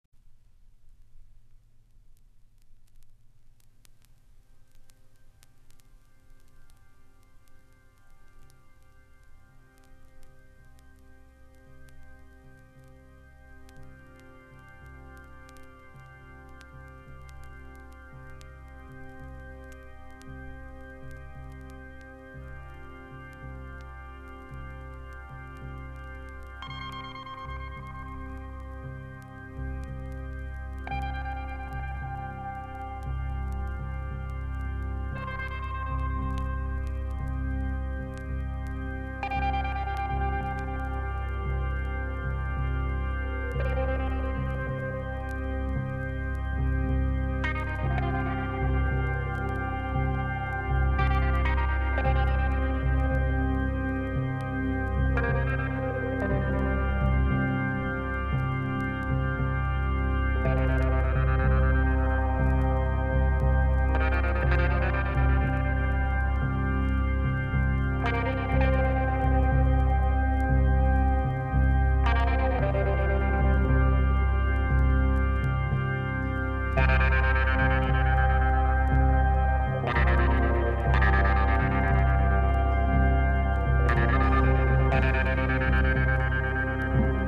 Jazz influenced Progressive rock approach